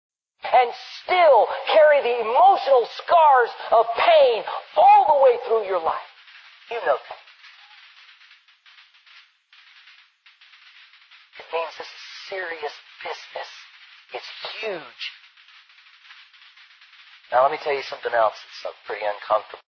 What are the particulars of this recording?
Here's the phone effect on the original MP3, plus a ton of compression as the final "sauce".